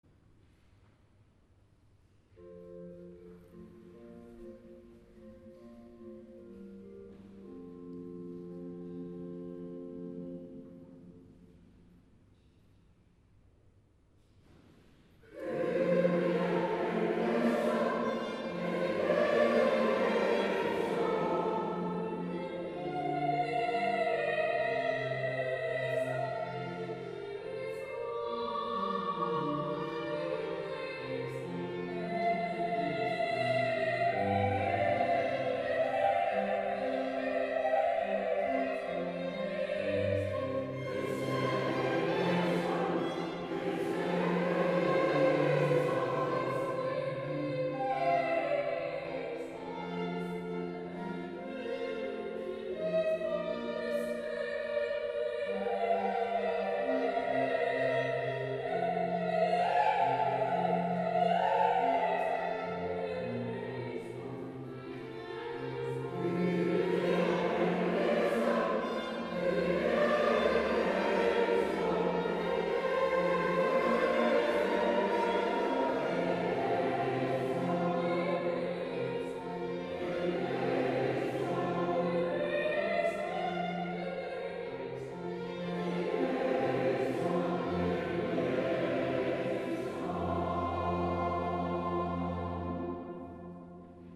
Das ist die Aufnahme des Kyrie aus der Missa in F von Joseph Haydn. Die Seniorenkantorei hat diese Messe am 1. Oktober zusammen mit dem Chor 60plus aus Mettmann und dem Dreiklangchor aus Köln gesungen.
Sopran